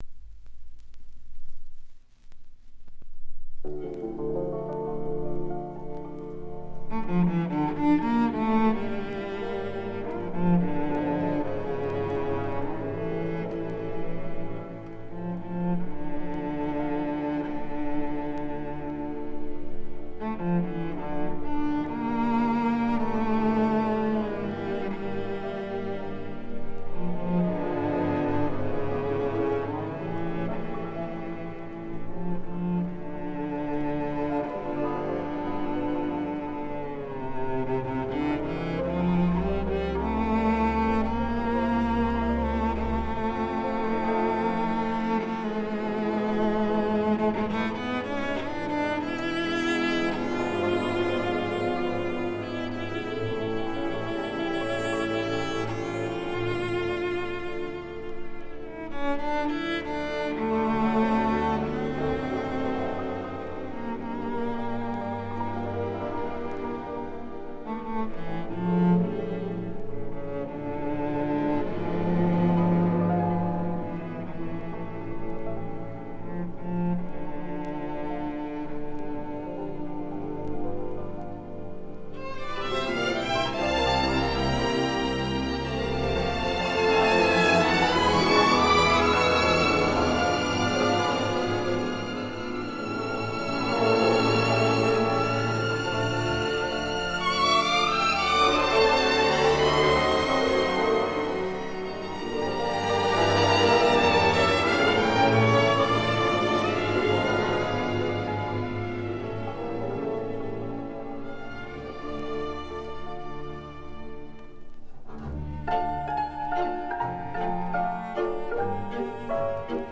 Оркестровая версия этой песни